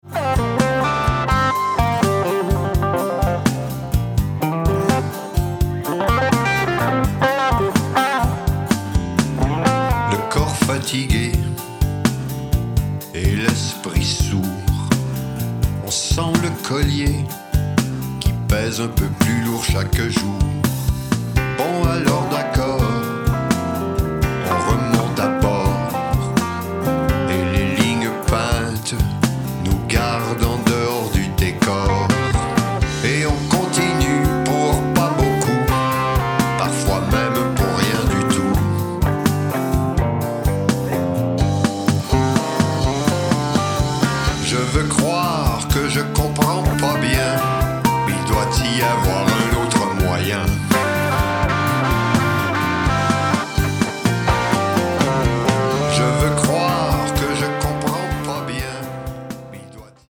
chante et  parle
guitariste
album électrique